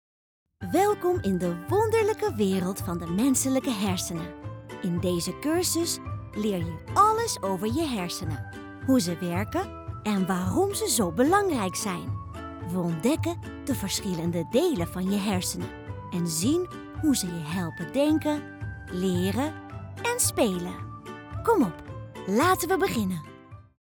Distinctive, Playful, Versatile, Friendly, Warm
Explainer